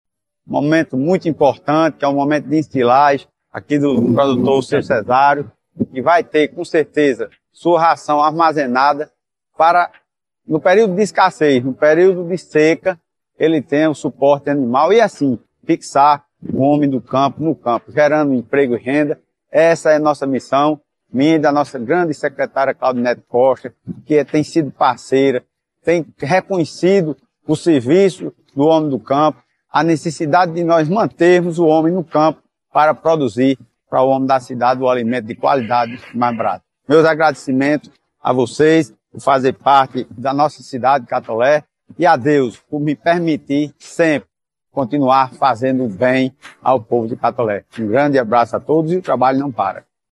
O Prefeito Laurinho Maia destaca mais uma ação de sua gestão que fortalece o pequeno e o médio produtor rural garantindo a alimentação do rebanho no período de seca.
ÁUDIO PREFEITO LAURINHO MAIA – ENSILAGEM